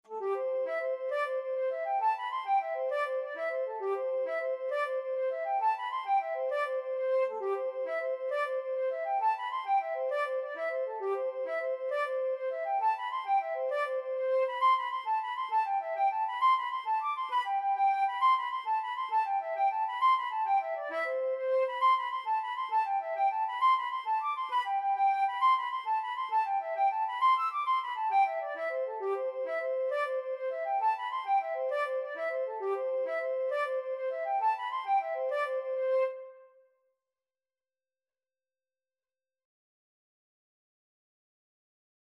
Flute version
6/8 (View more 6/8 Music)
G5-E7